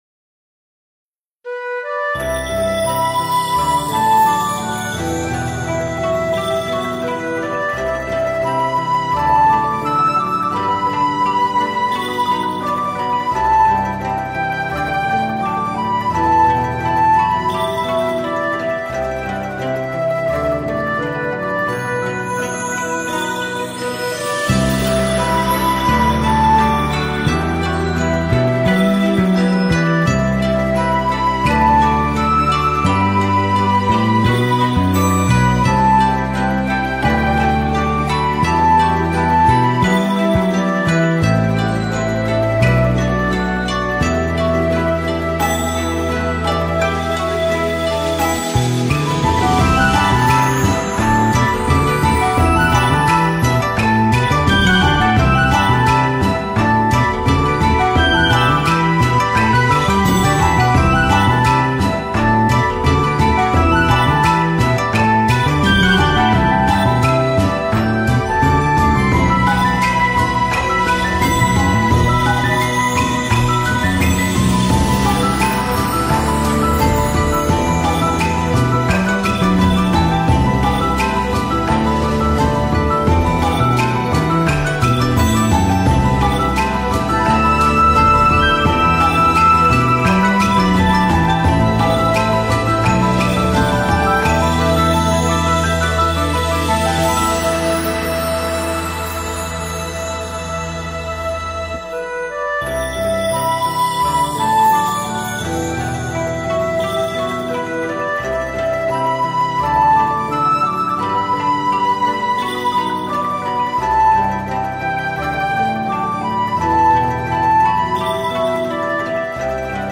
音频：康州觀音堂開光！2023年！